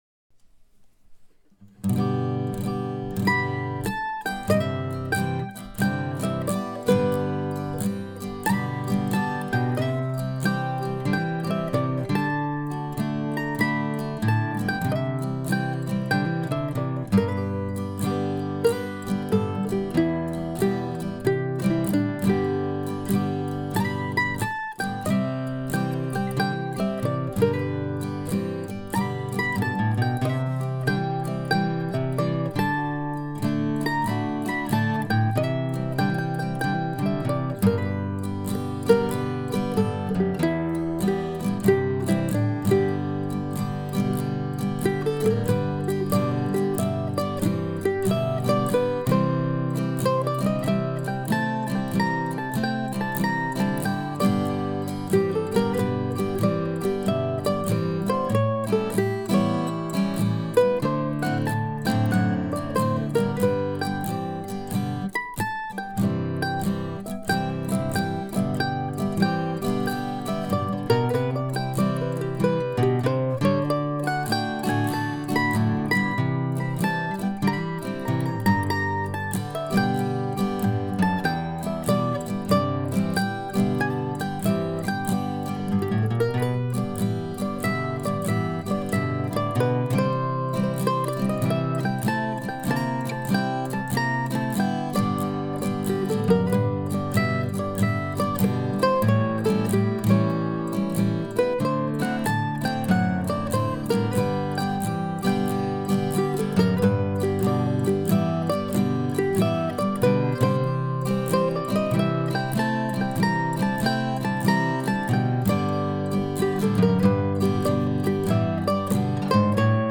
In any event, I hope you enjoy this relaxing little number from room no. 2 of car 2901.